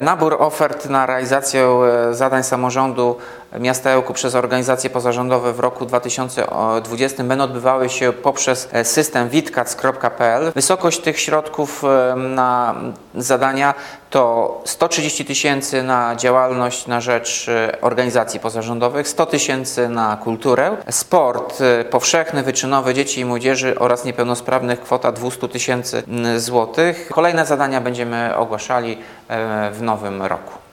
– Wnioski już można składać, będą one przyjmowane do wyczerpania środków – mówi Tomasz Andrukiewicz, prezydent Ełku.